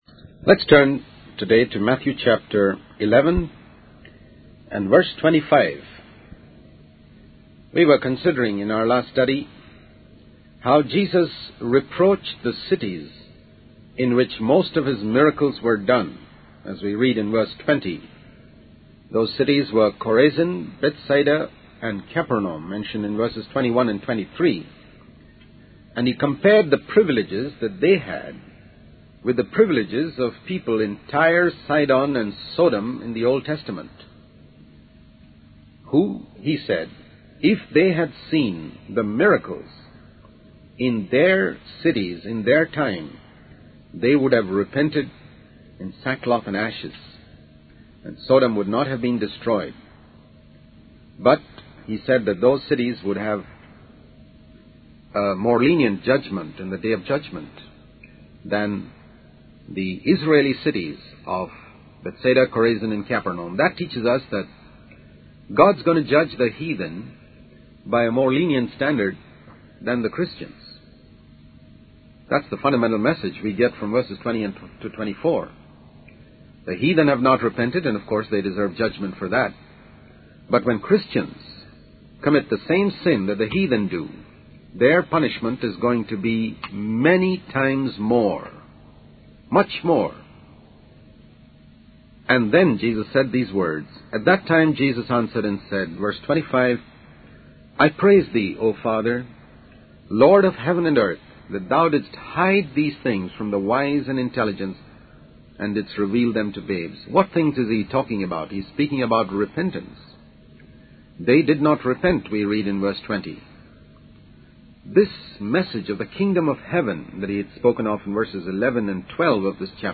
In this sermon, the preacher discusses the concept of finding rest in Jesus. He explains that this rest can only be obtained by responding to the call of Jesus and taking His yoke upon ourselves. The preacher uses the analogy of two bullocks under one yoke to illustrate the need to submit to Jesus' authority and give up our own will.